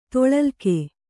♪ toḷalke